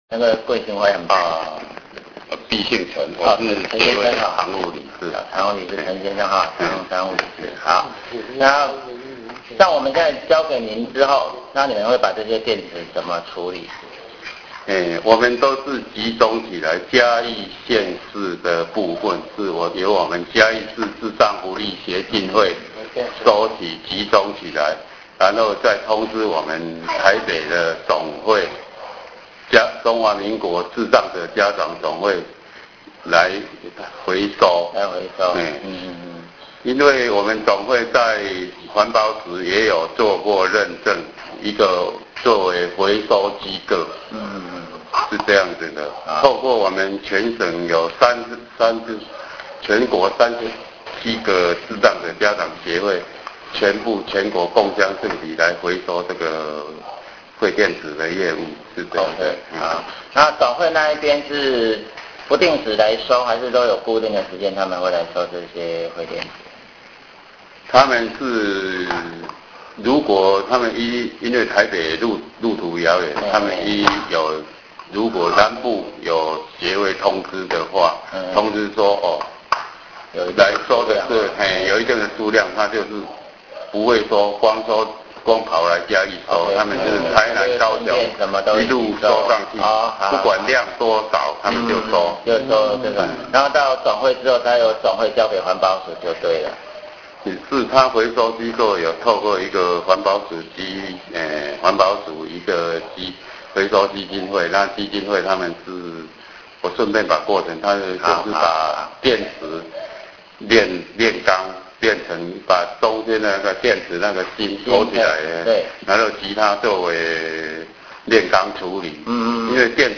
從三十分鐘的 訪談 中，我對該協會以及讓智障者有家的 [ 家 ] 有了更清晰的認識，回家之後便寫了 班群通訊( 一) ，一方面希望所有的小狗狗們能夠從中知道經過了半個月的努力，我們已經有了一些具體的成果，一方面也要當日到天使回收站所獲得的重要訊息告訴大家，它的內容是這樣：